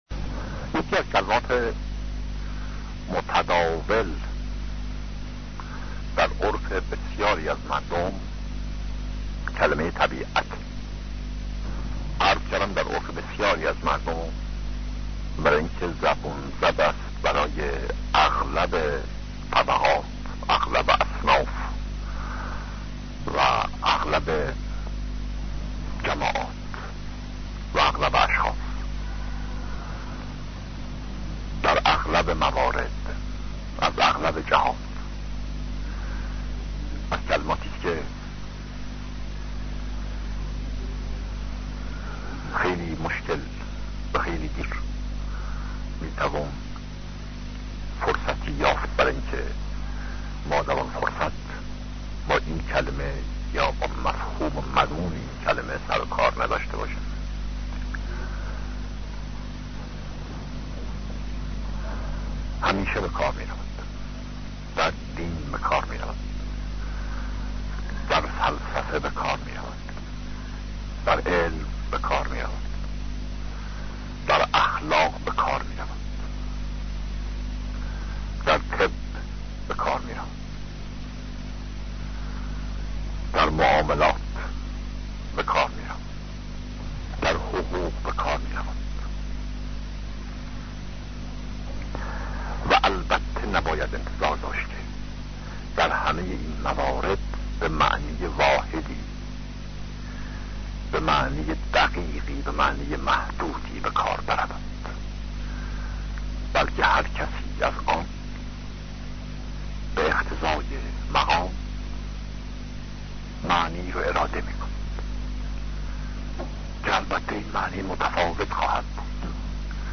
سخنرانی هایی پیرامون عقاید بهائی
ببخشید از اواسط این سخنرانی ، کیفیت صدا بسیار بد می شود و اصلا قابل فهم نیست ممنون می شم اگر تغییری ایجاد کنید.